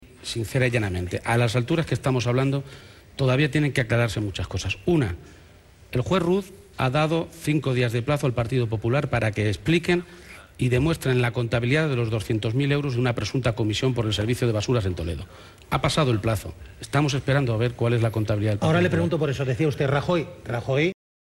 García-Page se pronunciaba de esta manera en una entrevista en el programa “Al Rojo Vivo”, de La Sexta, en la que insistía en que Cospedal reconoció la recepción de esos 200.000 euros.
Cortes de audio de la rueda de prensa
Page-entrevista_al_rojo_vivo_1.mp3